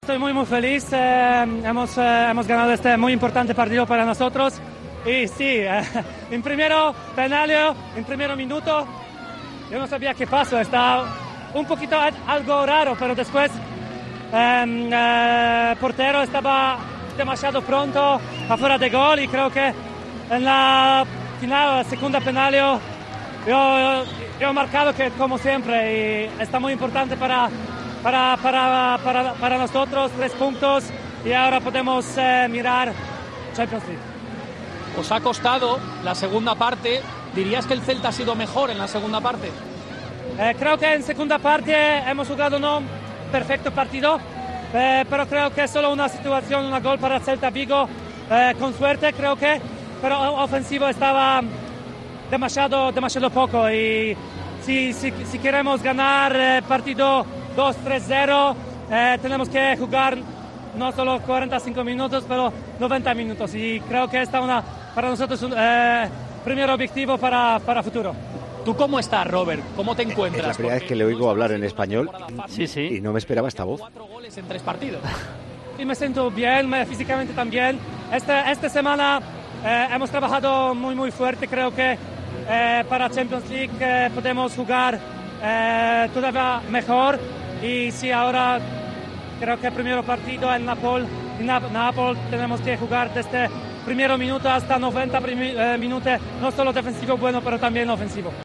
Entrevista a pie de campo